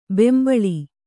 ♪ bembaḷi